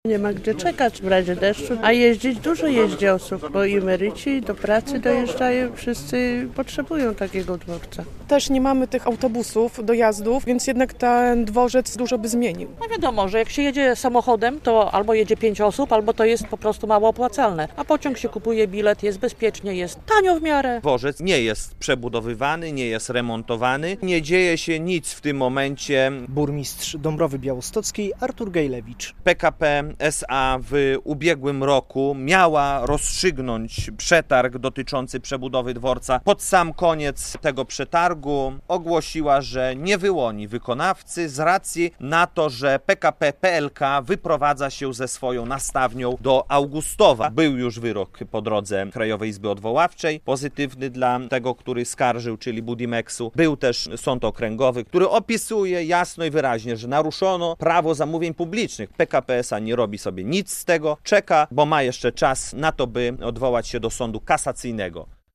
Mieszkańcy władze Dąbrowy Białostockiej walczą o remont dworca - relacja